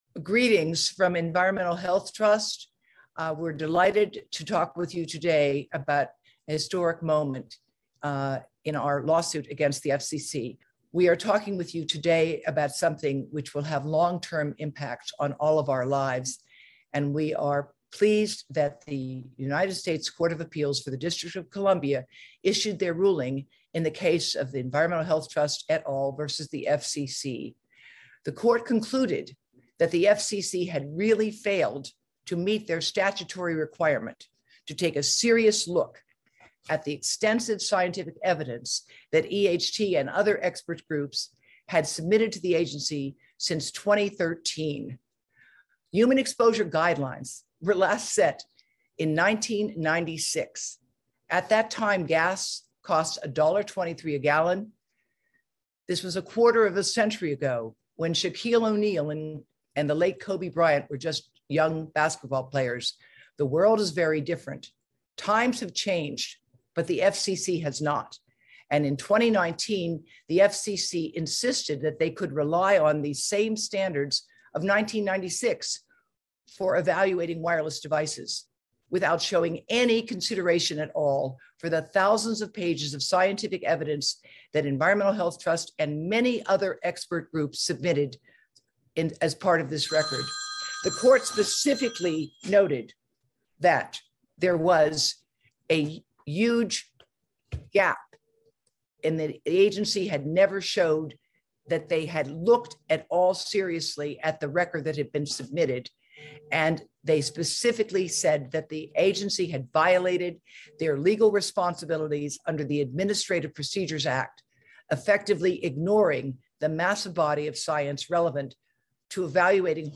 EHT Wins in EHT v. FCC Federal Lawsuit - Press Conference